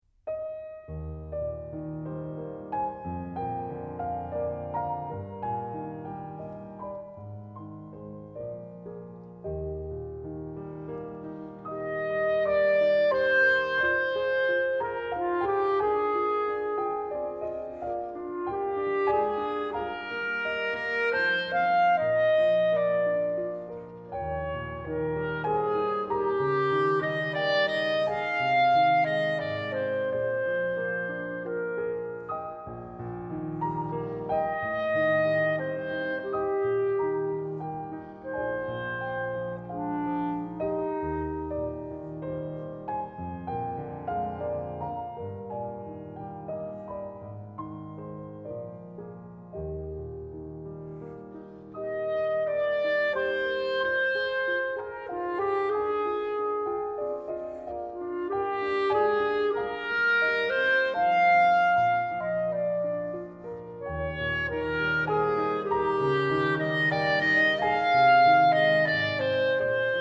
純真質樸的管樂，加上觸鍵輕柔鋼琴，旋律單純，卻令人陶醉。
Clarinet
Piano)audio_joy.JPG